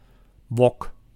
Ääntäminen
Ääntäminen France (Île-de-France): IPA: /wɔk/ Haettu sana löytyi näillä lähdekielillä: ranska Käännös Konteksti Ääninäyte Substantiivit 1. wok {m} ruoanlaitto Suku: m .